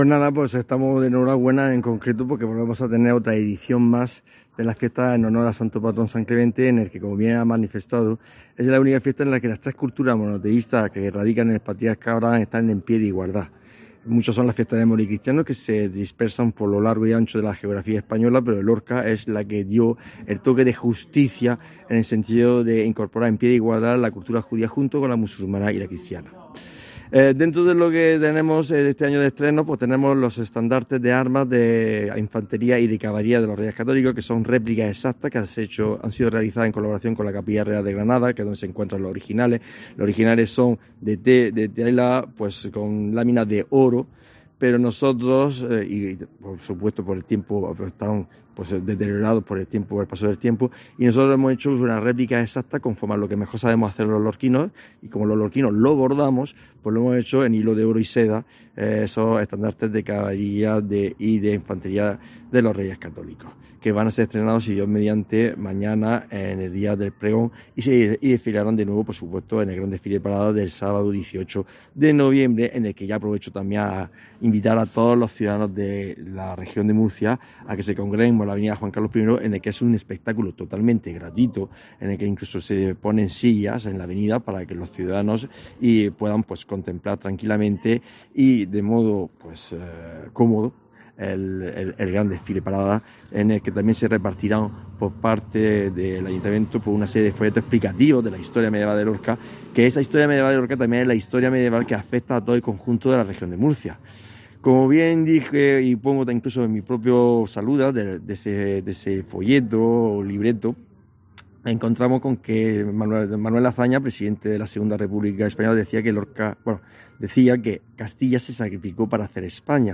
La entrevista la hemos llevado a cabo en la antigua iglesia de Santa María la Mayor, sede del ciuFRONT, Museo Medieval de Lorca, que tiene prevista su apertura como Museo a principio de año 2024.